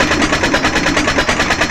c_spiker_atk3.wav